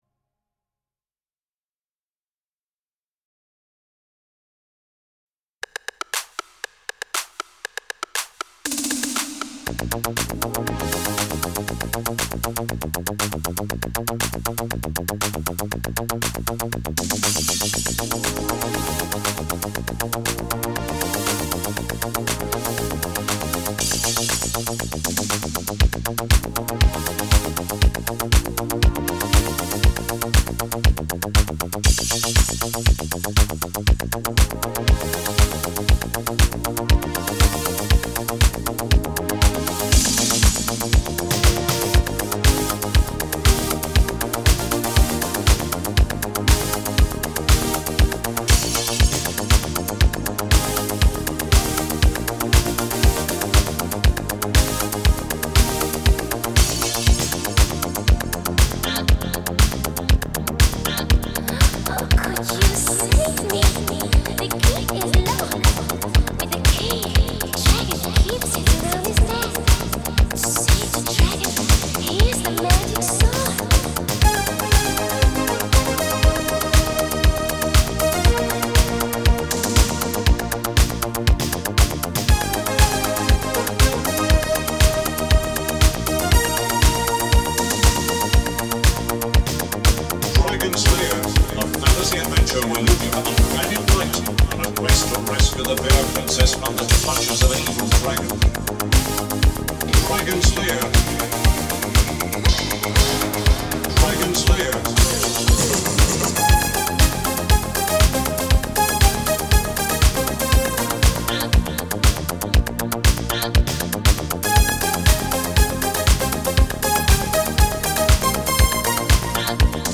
Жанр: Disco